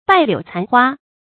败柳残花 bài liǔ cán huā
败柳残花发音
成语注音 ㄅㄞˋ ㄌㄧㄨˇ ㄘㄢˊ ㄏㄨㄚ